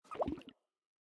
Minecraft Version Minecraft Version snapshot Latest Release | Latest Snapshot snapshot / assets / minecraft / sounds / mob / axolotl / idle5.ogg Compare With Compare With Latest Release | Latest Snapshot